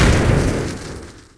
fireimpact03.wav